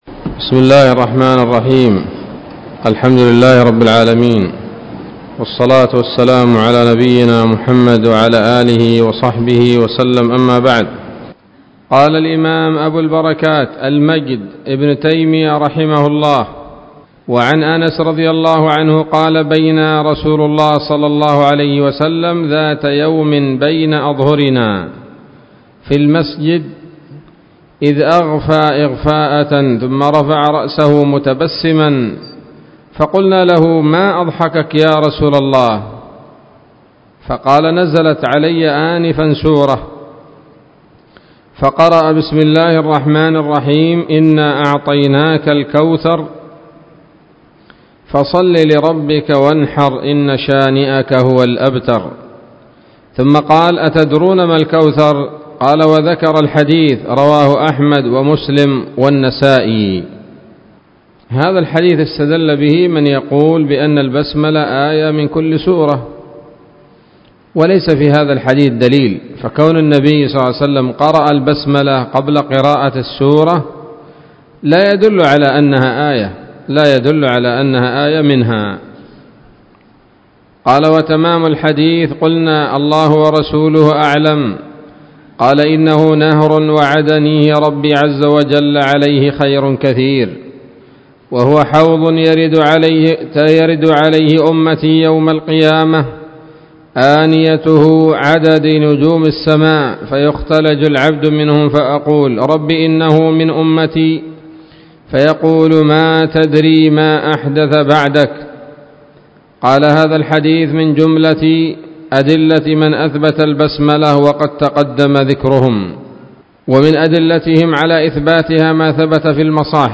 الدرس السابع والعشرون من أبواب صفة الصلاة من نيل الأوطار